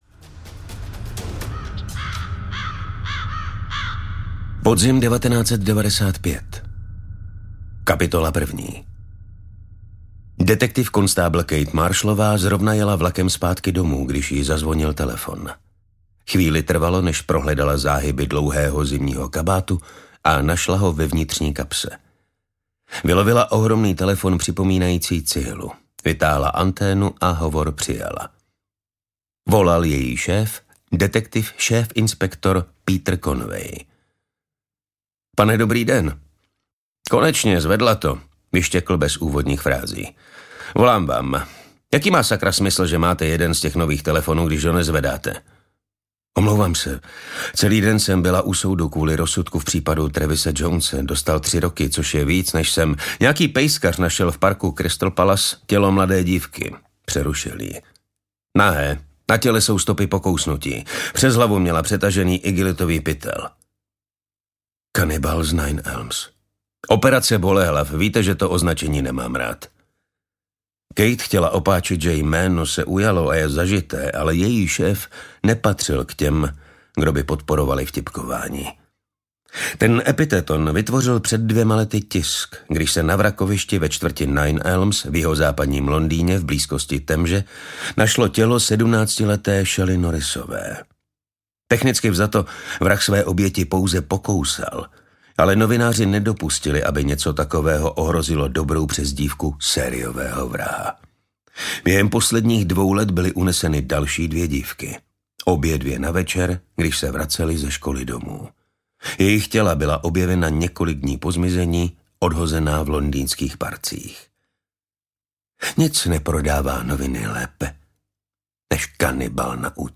Audio knihaKanibal z Nine Elms
Ukázka z knihy